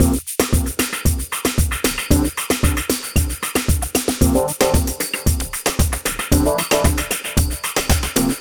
Ala Brzl 2 Full Mix 2a-C.wav